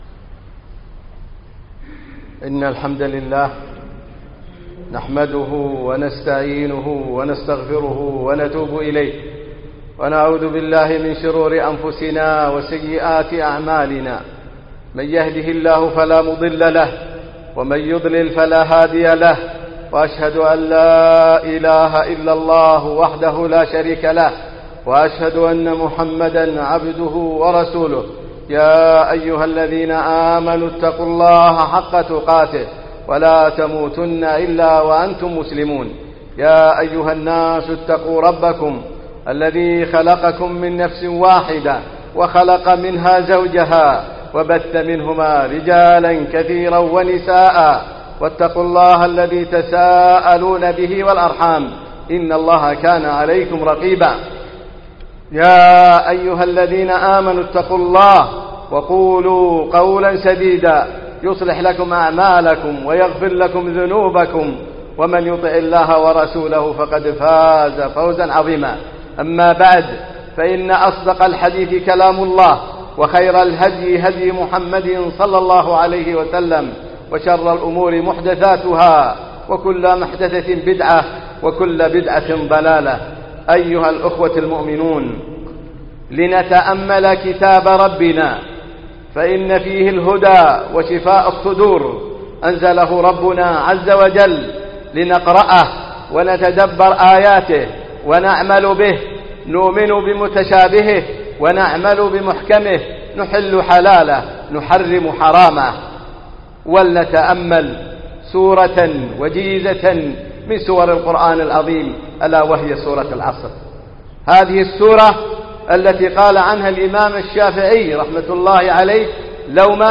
الجمعة 18 شعبان 1436 الموافق 5 6 2015 مسجد الكليب قرطبة
سورة العصر - خطبة